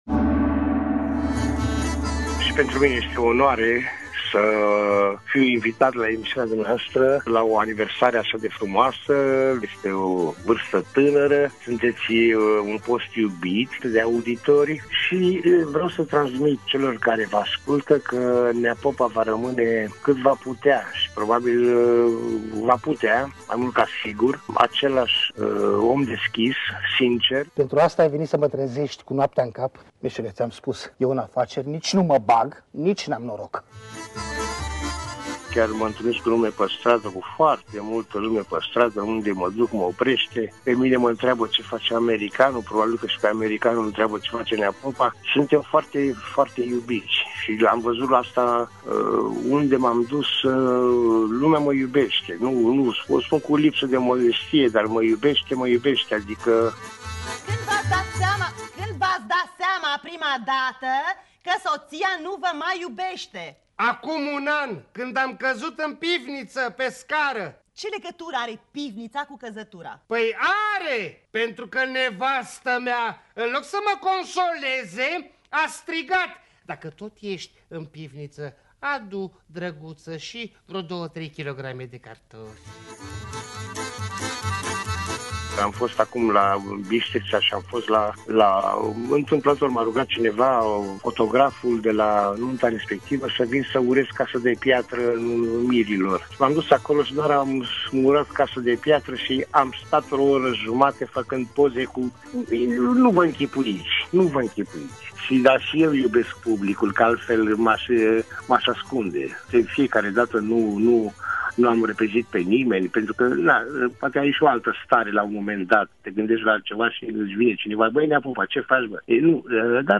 Actorul Tudorel Filimon este invitatul rubricii ”Sus cortina!”, de la ora 17.15, în emisiunea ”Drum cu prioritate”, chiar în ziua în care EUROPA FM împlinește 15 ani.